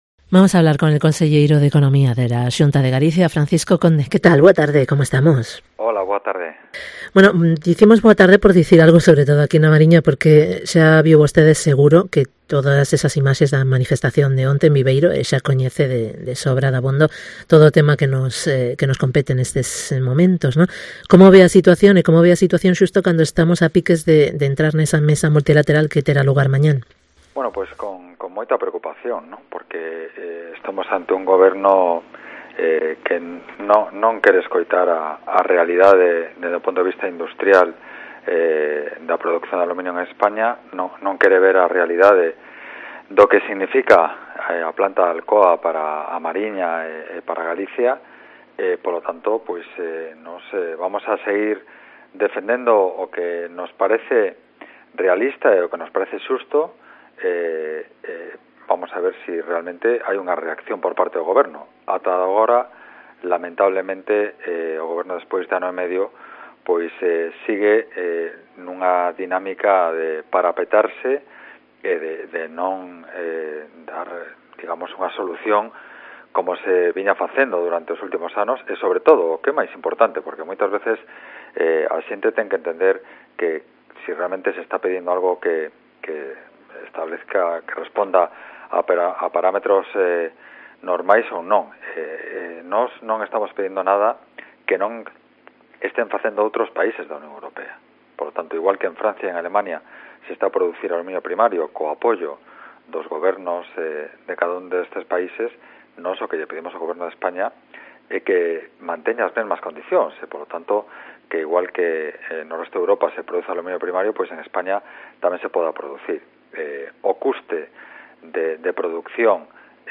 Entrevista con FRANCISCO CONDE, conselleiro de Economía